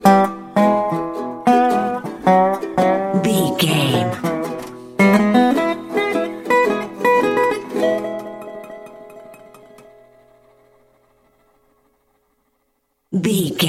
Ionian/Major
acoustic guitar
electric guitar
ukulele
slack key guitar